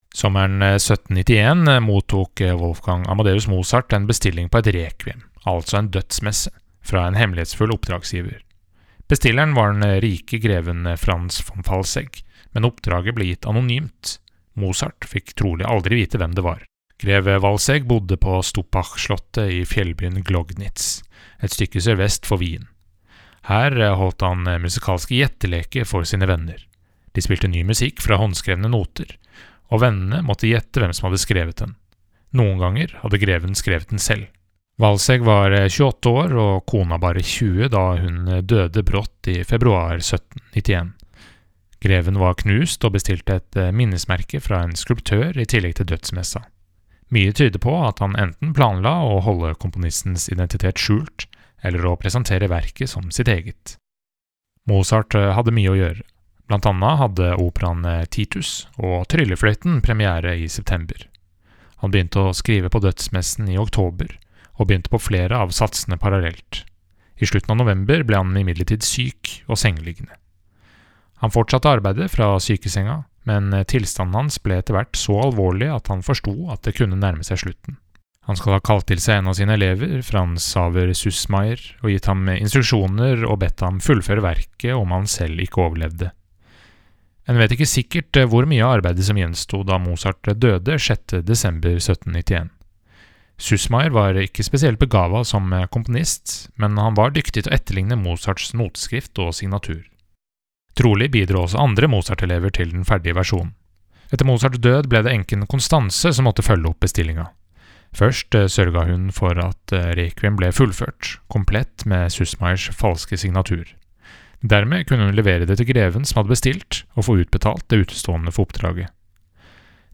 VERKOMTALE-Wolfgang-Amadeus-Mozarts-Requiem.mp3